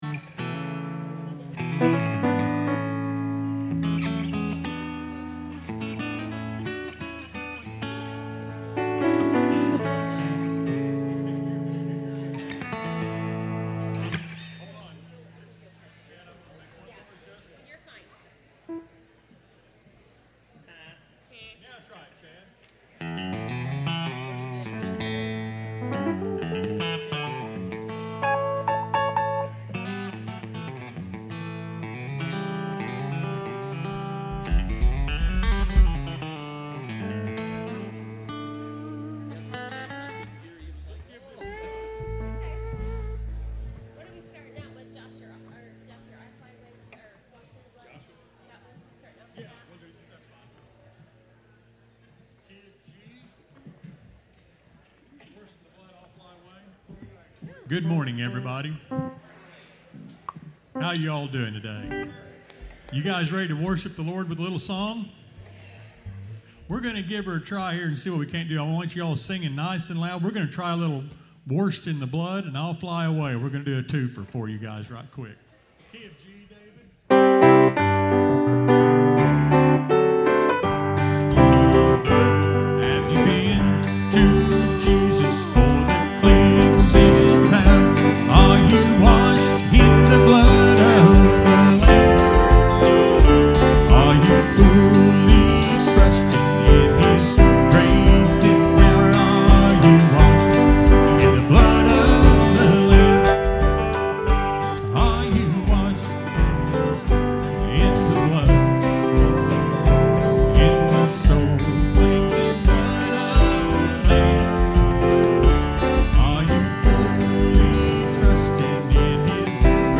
preaches from Acts to discuss how God has a plan for each of us. He informs us that we all have been put here for a purpose and today he looks at what that purpose is.